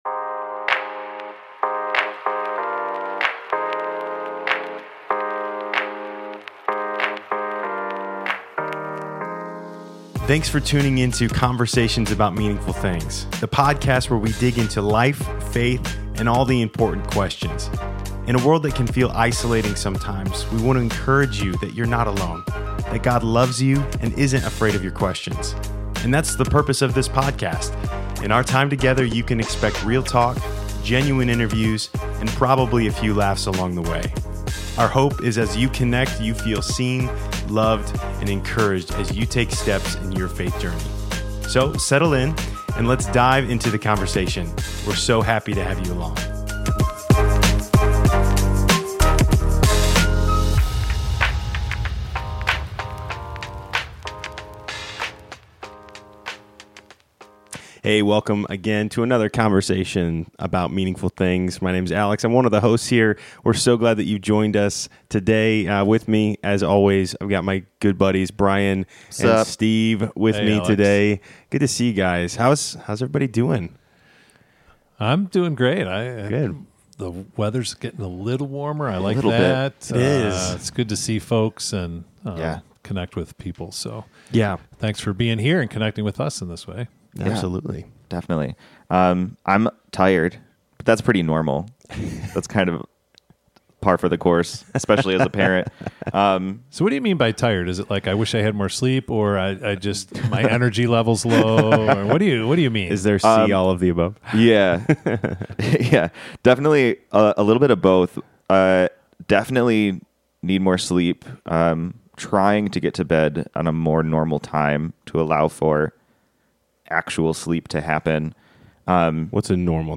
When was the last time you fasted — not just skipped a meal, but truly sought God through the powerful (and often neglected) spiritual discipline of fasting? In today’s episode, the guys dive deep into what fasting really means, why it’s more than just going without food, and how it can unlock a deeper intimacy with God.